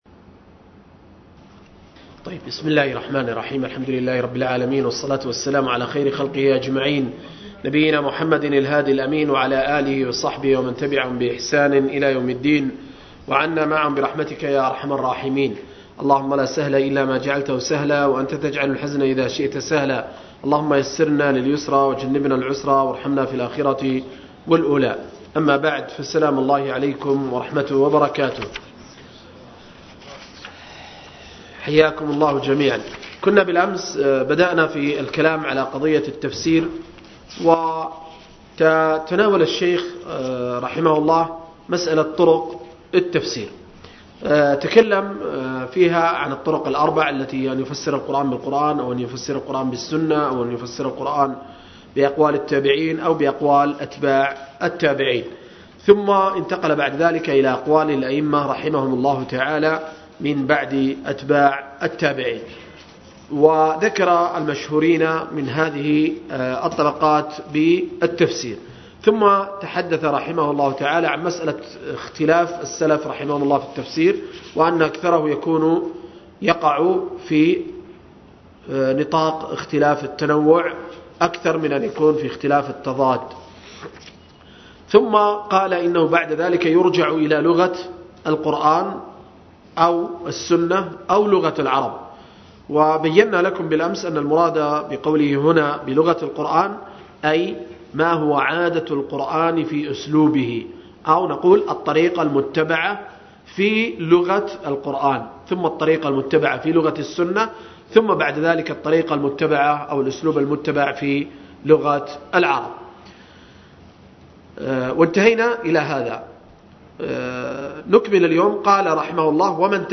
06-مقدمة التفسير للشيخ ابن قاسم رحمه الله – الدرس السادس